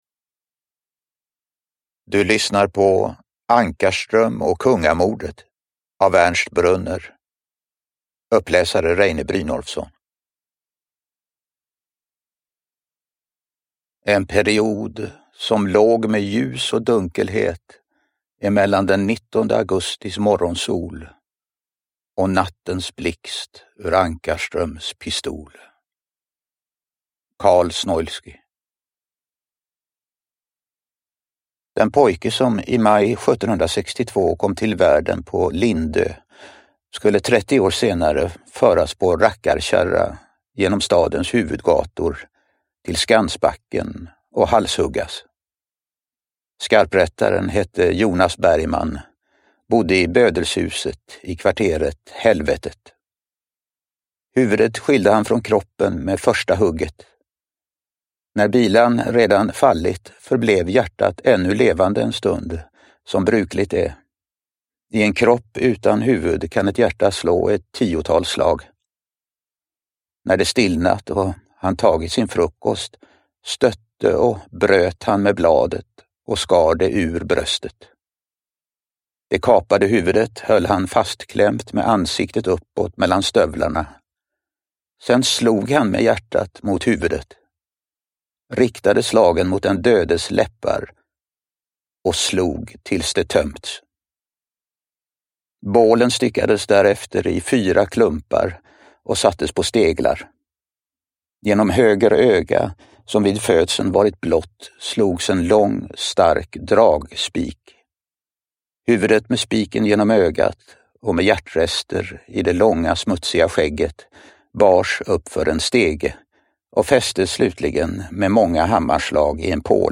Uppläsare: Reine Brynolfsson